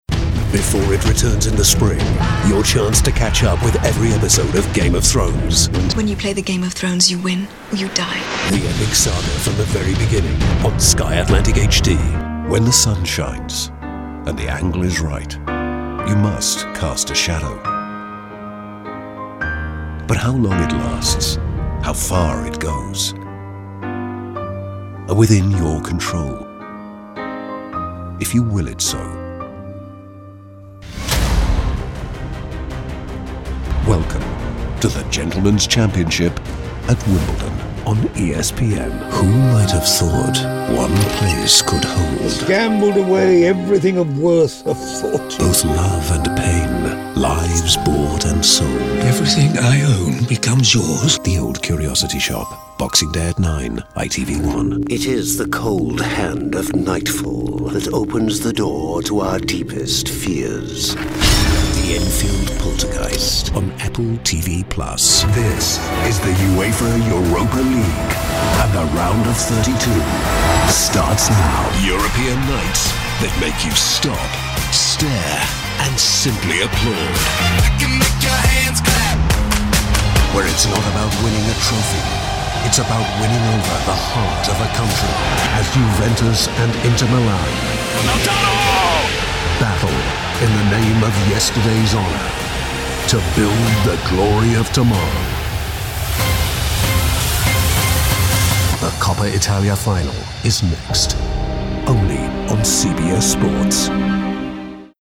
Englisch (Britisch)
Filmtrailer
Meine Stimme wird normalerweise als warm, natürlich und unverwechselbar beschrieben und wird oft verwendet, um Werbe- und Erzählprojekten Klasse und Raffinesse zu verleihen.
Neumann U87-Mikrofon